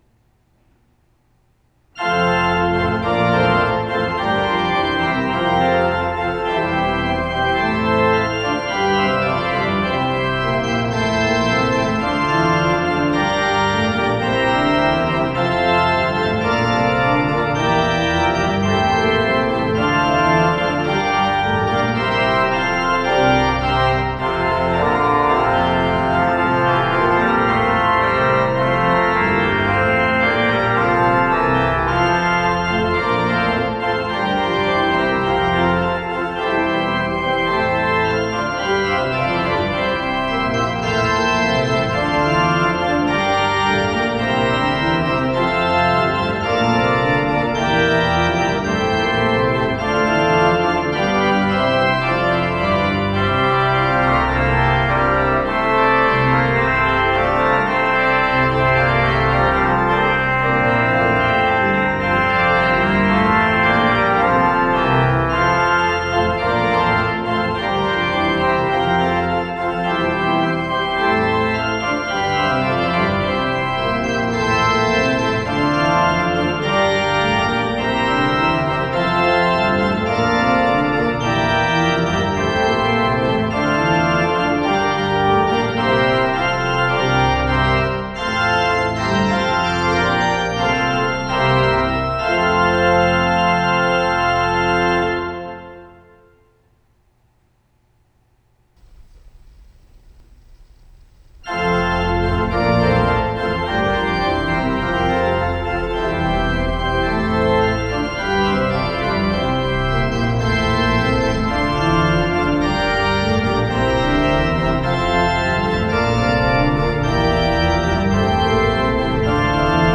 Tetrahedral Ambisonic Microphones
Listener Survey Sample #2: Casavant Freres Organ (06:26) (39MB/file).
Complete 4.1 stems from each of the 3 microphones.